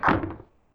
gun_shot.wav